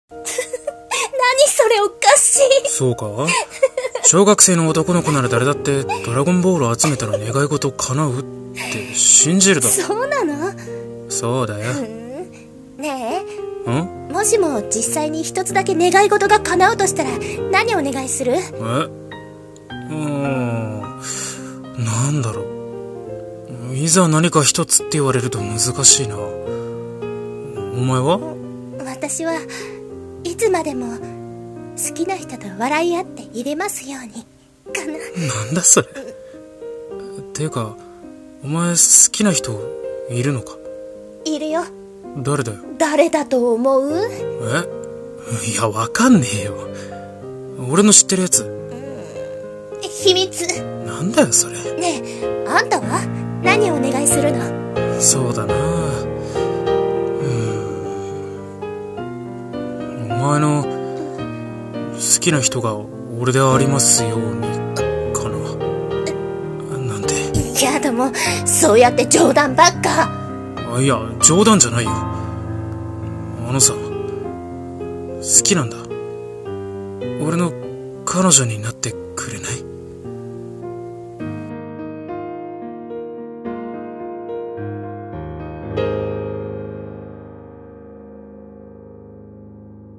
声劇【放課後の教室】